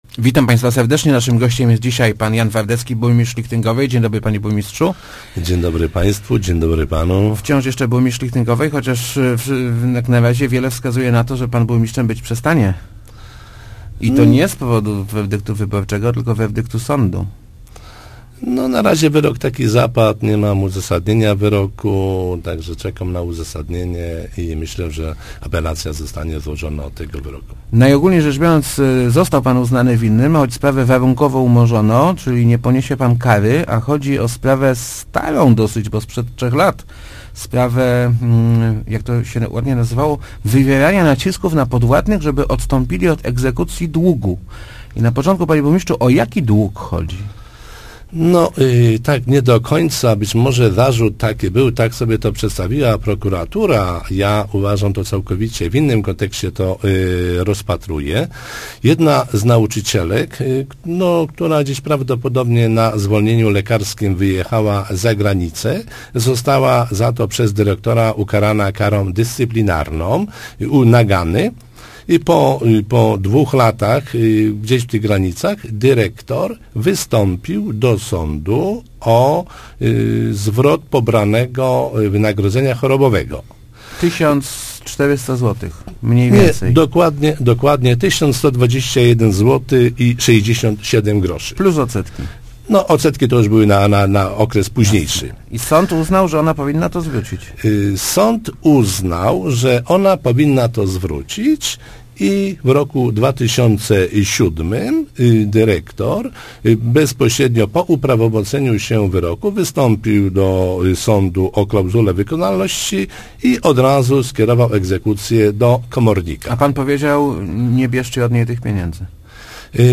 Czekam na uzasadnienie – mówi� w Rozmowach Elki burmistrz Szlichtyngowej Jan Wardecki, komentuj�c orzeczenie s�du, który warunkowo umorzy� spraw� wywierania nacisków na podw�adnych, by ci odst�pili od egzekucji nale�no�ci od d�u�niczki gimnazjum. Burmistrz zapewnia, �e nie ma sobie nic do zarzucenia.